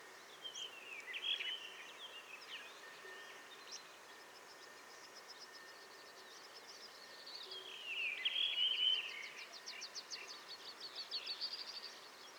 rufous tailed rock thrush
Monticola saxatilis